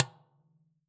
Dustbin1.wav